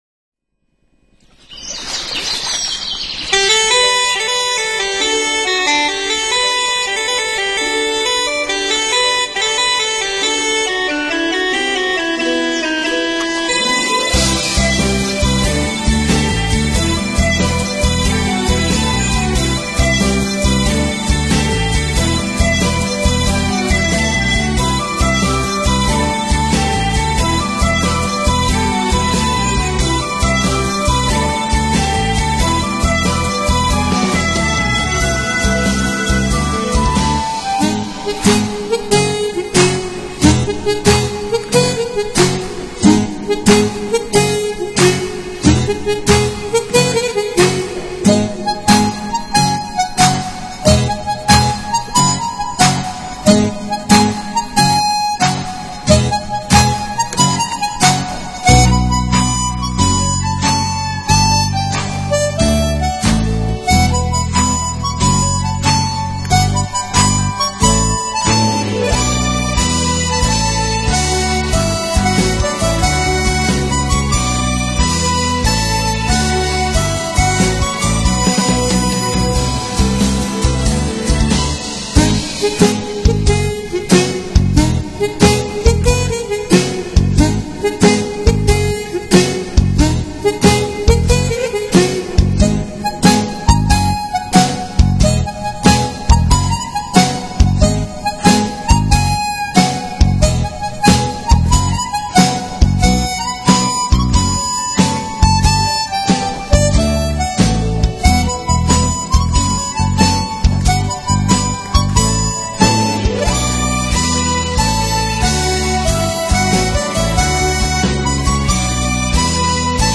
非常震撼的曲子
中間輕快那段太喜歡了